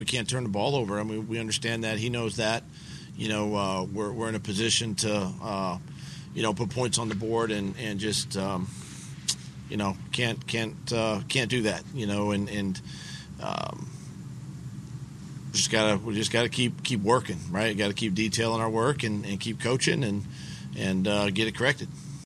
Coach Peterson talked about Wentz and his turnover problems.